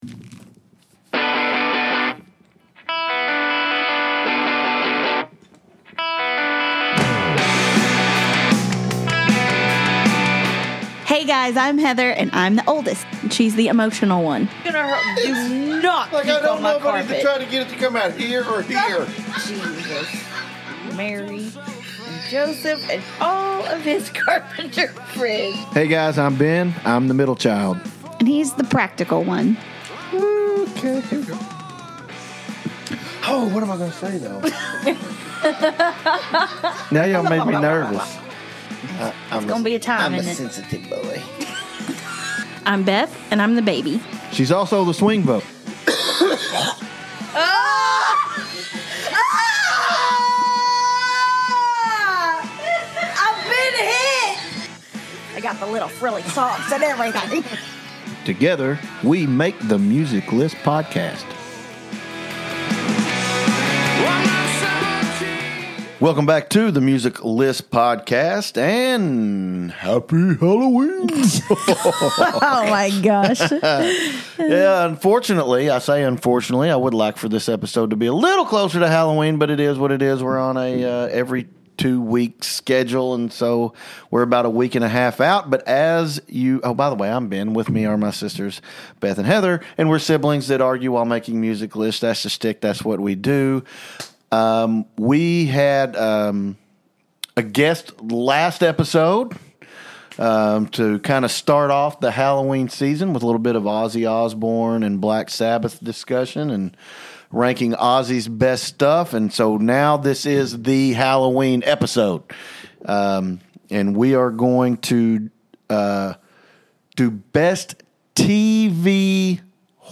The Music List Podcast takes a recipe for entertainment- a hotly debated musical topic, three siblings who love to argue, and open mics, and then tosses them into a mixing bowl.